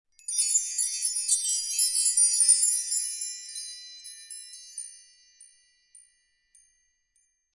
Download Wind Chimes sound effect for free.
Wind Chimes